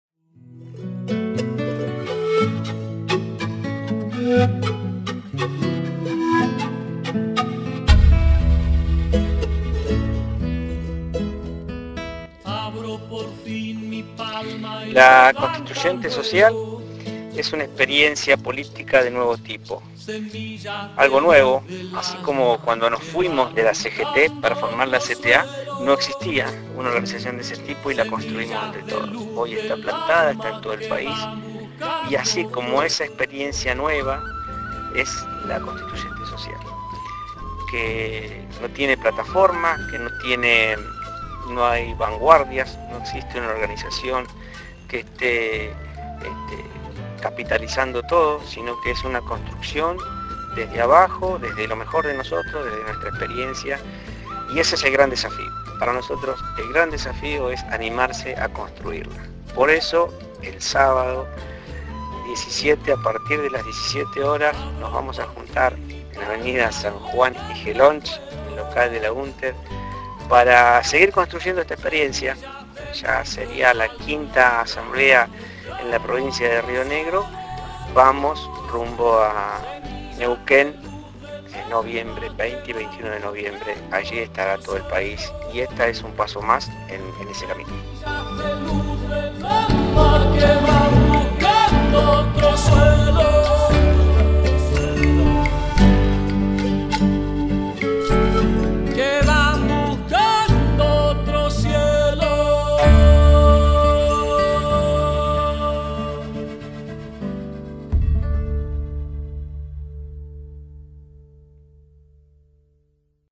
Río Negro, spot